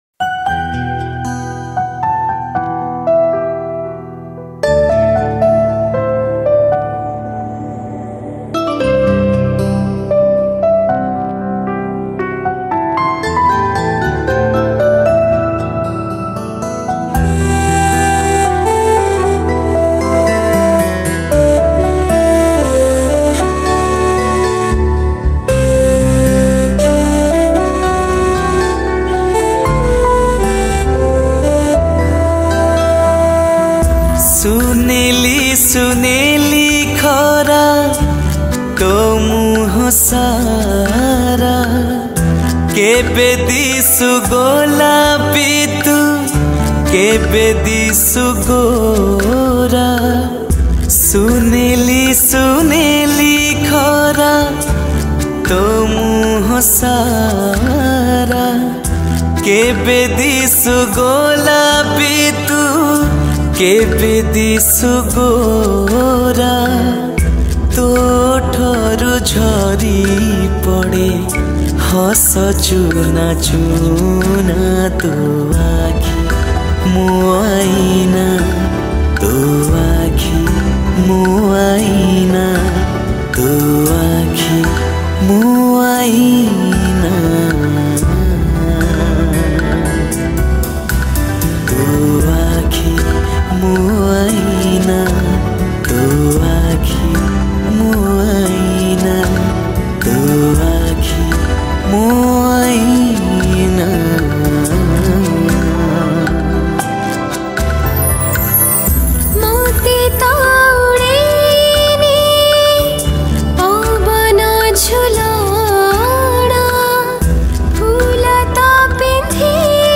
Odia Cover Song